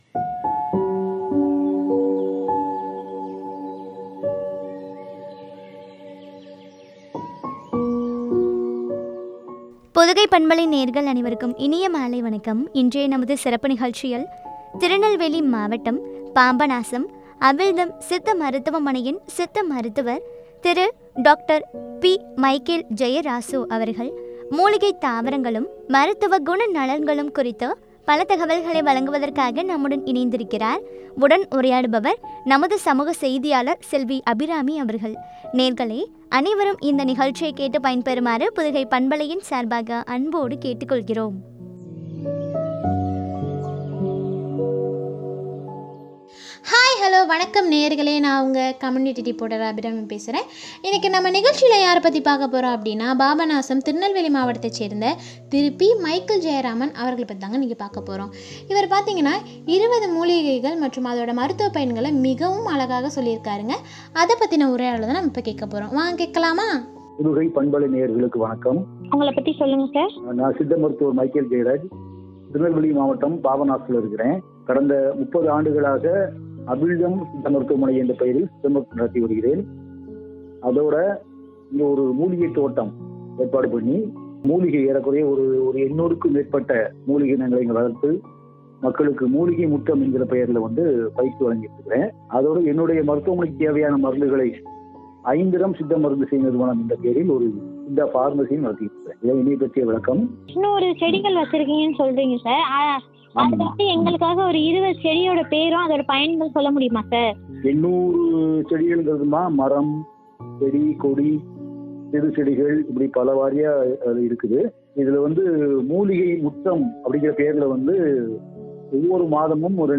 மருத்துவ குணம்நலன்களும் பற்றிய உரையாடல்.